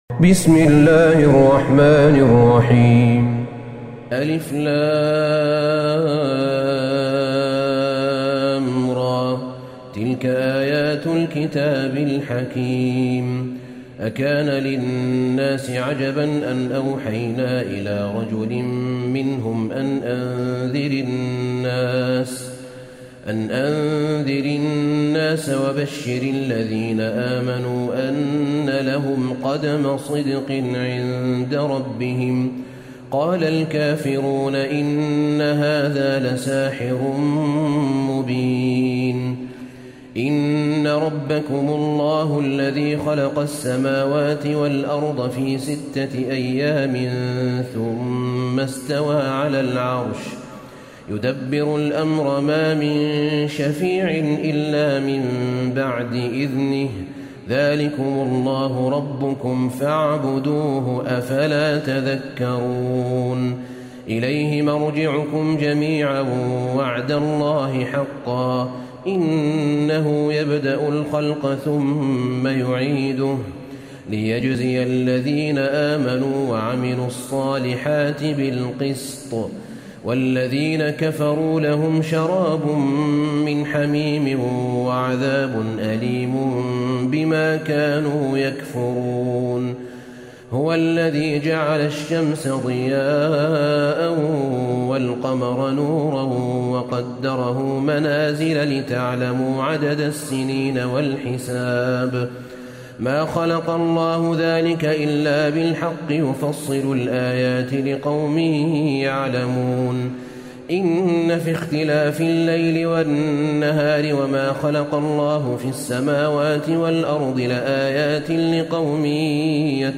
سورة يونس Surat Yunus > مصحف الشيخ أحمد بن طالب بن حميد من الحرم النبوي > المصحف - تلاوات الحرمين